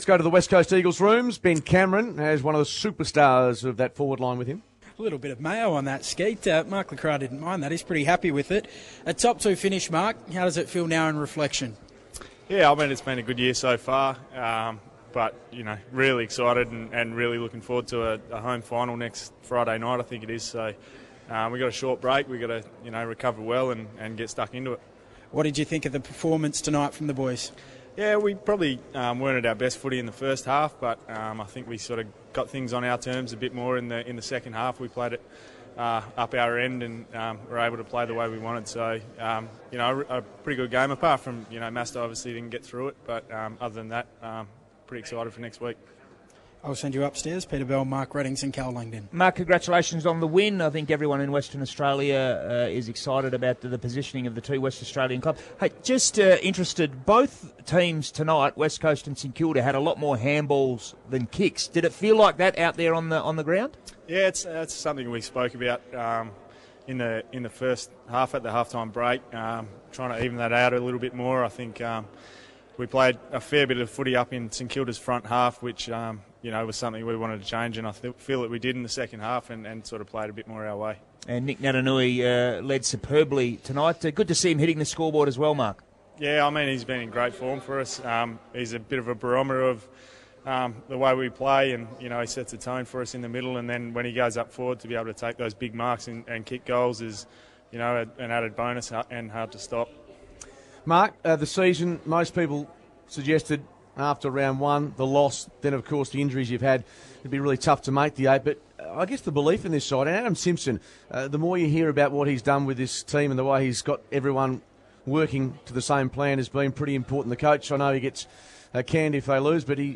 Mark LeCras post game interview
Mark LeCras speaks to the 6PR commentary team and sums up the game and gives his tips for Brownlow votes